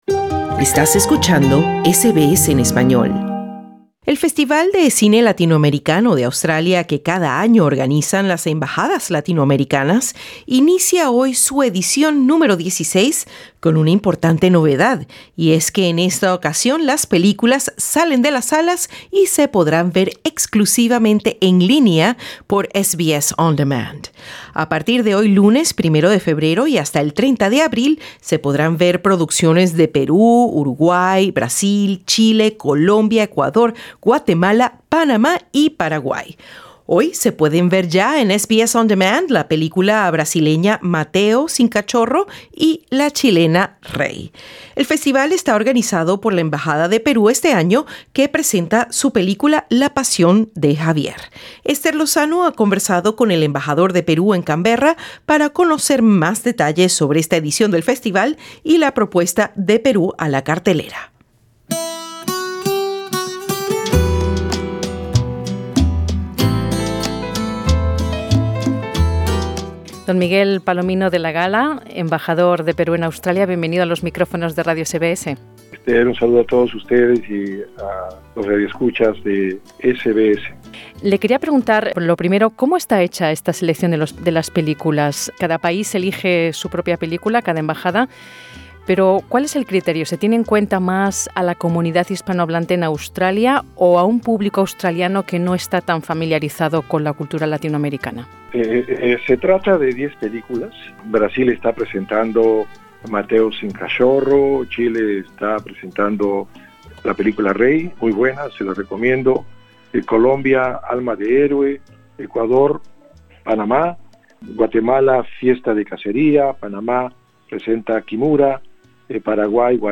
Escucha la entrevista con el embajador de Perú en Camberra, Miguel Palomino De La Gala, para conocer más detalles sobre el festival y la propuesta de Perú a la cartelera.